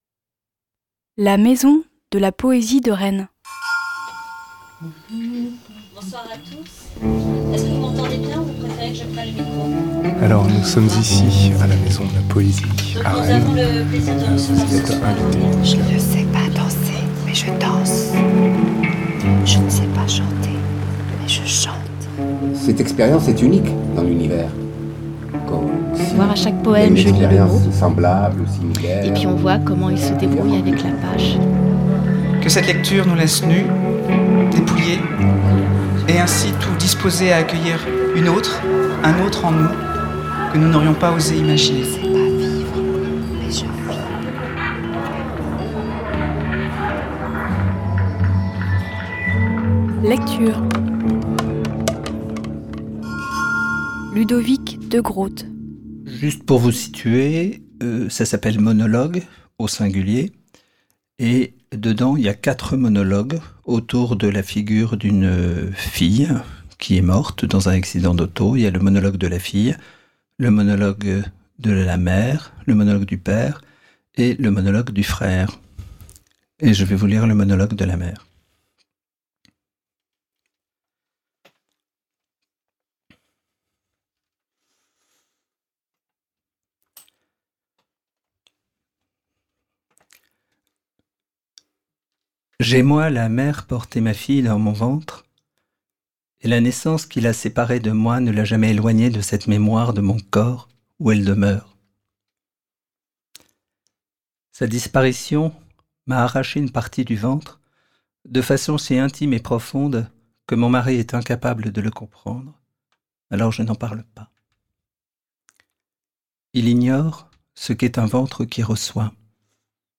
Lecture à l’occasion de l’édition 2012 du festival des Polyphonies organisé par la maison de la Poésie de Rennes.